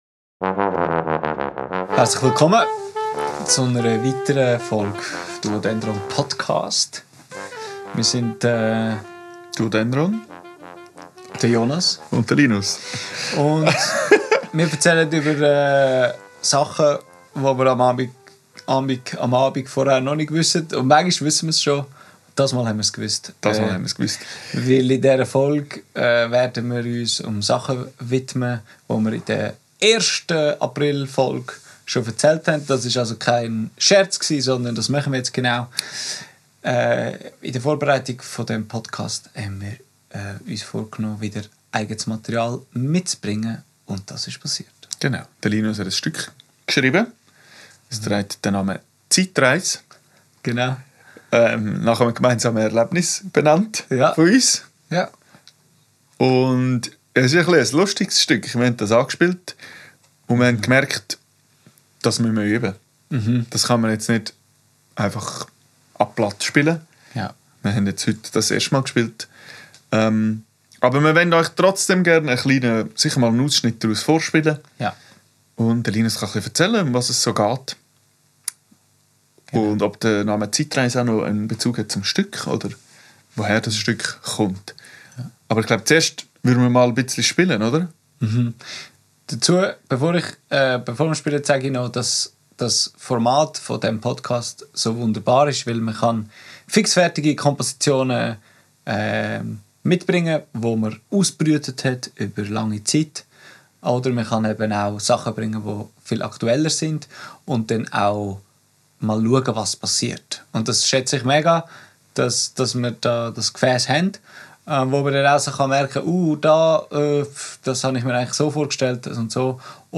Darum wird es in dieser Folge erst einmal ein wenig vorgestellt und die Geschichte dahinter erzählt. Aufgenommen am 25.03.2025 im Atelier